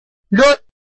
拼音查詢：【饒平腔】lud ~請點選不同聲調拼音聽聽看!(例字漢字部分屬參考性質)